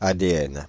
Ääntäminen
Synonyymit acide désoxyribonucléique Ääntäminen Paris: IPA: [a.de.ɛn] France (Île-de-France): IPA: /a.de.ɛn/ Haettu sana löytyi näillä lähdekielillä: ranska Käännös Konteksti Substantiivit 1.